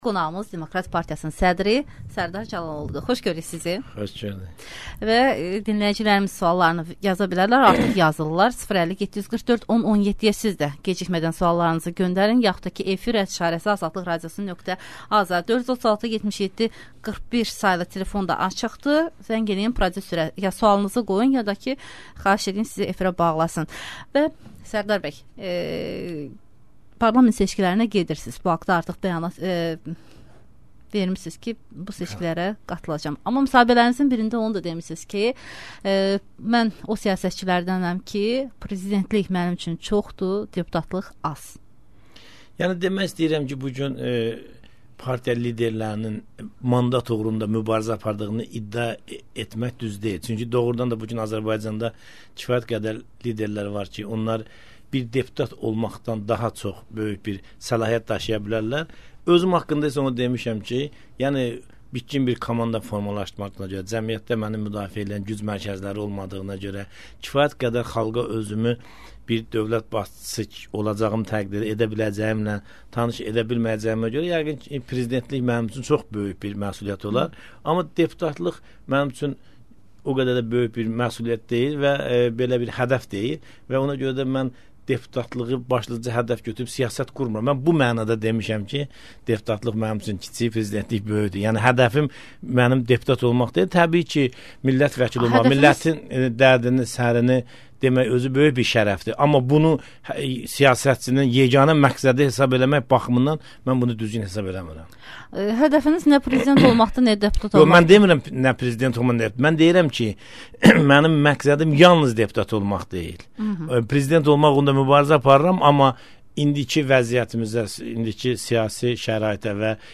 Bunu AzadlıqRadiosunun «İşdən sonra» proqramında Azərbaycan Demokrat Partiyasının sədri Sərdar Cəlaloğlu deyib.